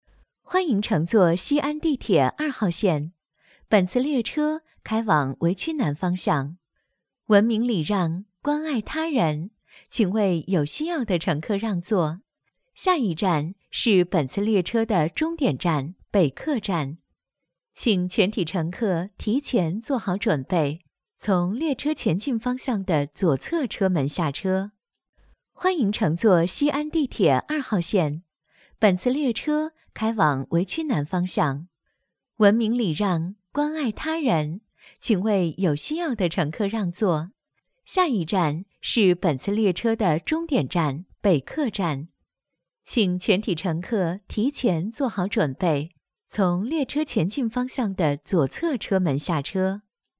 • 女S12 国语 女声 语音播报 西安地铁二号线-地铁报站-甜美 积极向上|时尚活力|亲切甜美